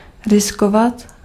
Ääntäminen
IPA: [a.fʁɔ̃.te]